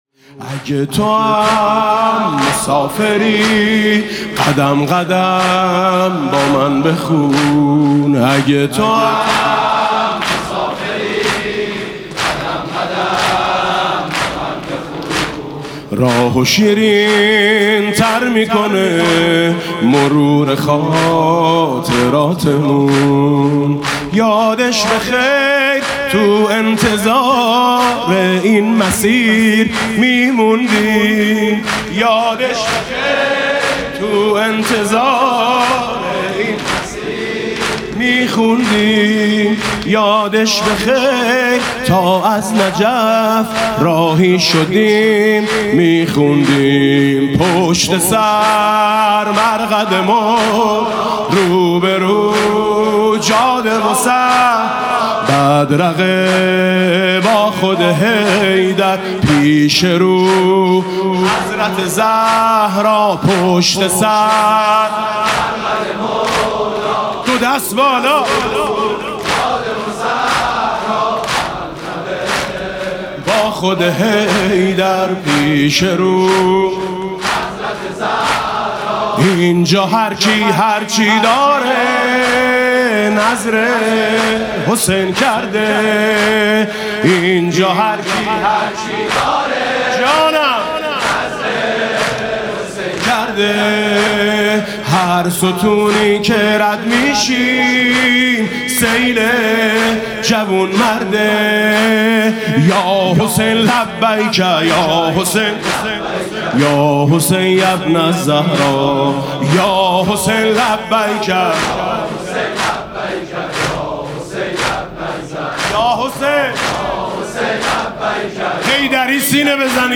مسیر پیاده روی نجف تا کربلا [عمود ۹۰۹]
مناسبت: ایام پیاده روی اربعین حسینی
زمینه ترکیبی اربعین